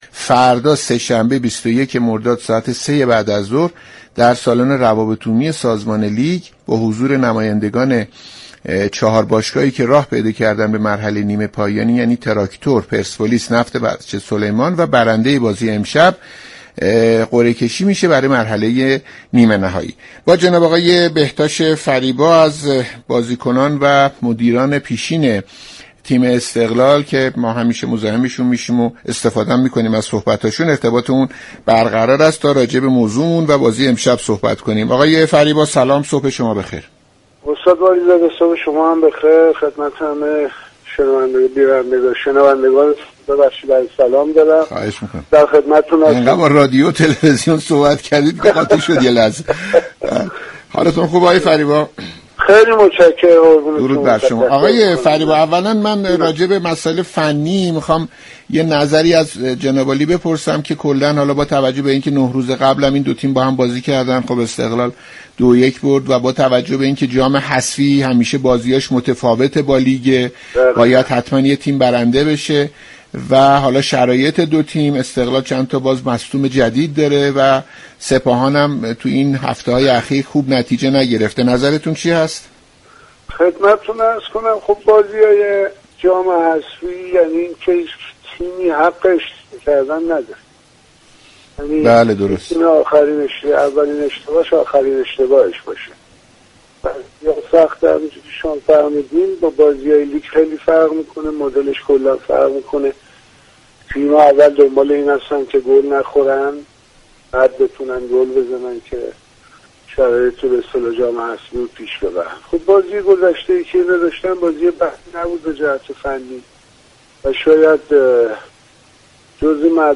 شما می توانید از طریق فایل صوتی پیوست شنونده این گفتگو باشید.